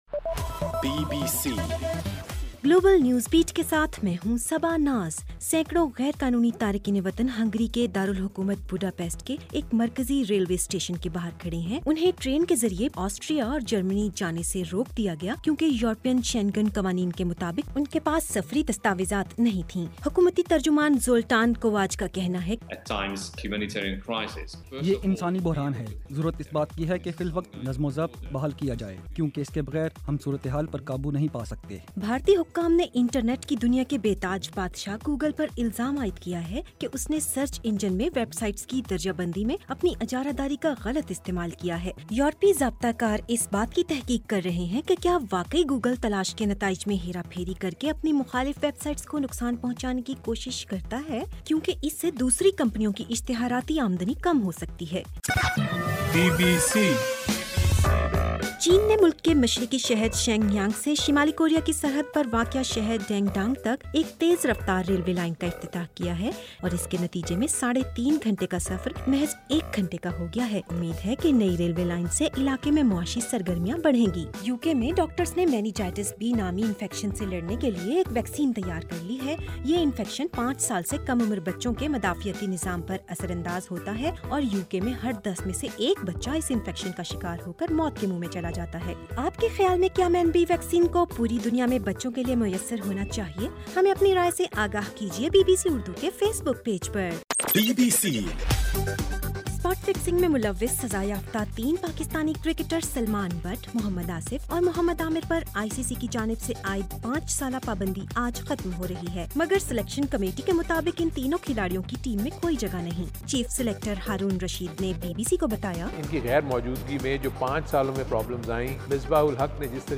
ستمبر 2: صبح 1 بجے کا گلوبل نیوز بیٹ بُلیٹن